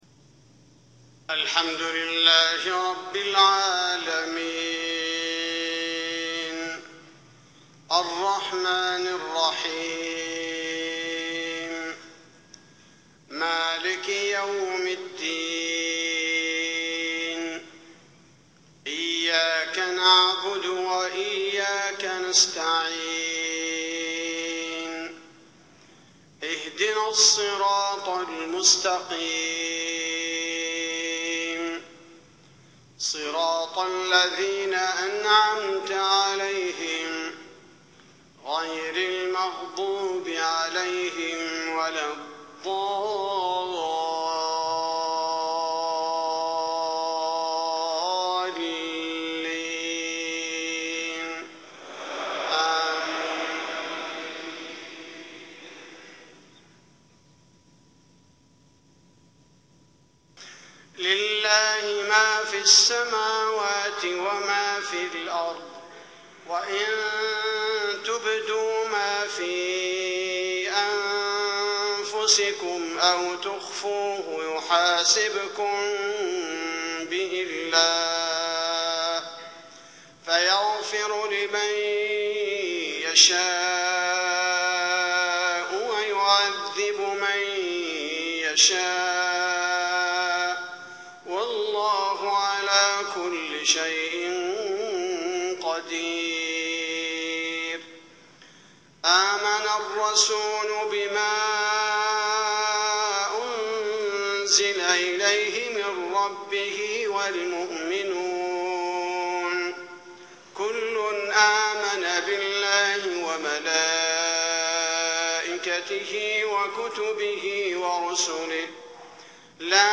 صلاة المغرب 1-7-1436 من سورة البقرة > 1436 🕌 > الفروض - تلاوات الحرمين